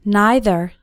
Both either and neither have two possible pronunciations:
Pronunciation #1 – NEITHER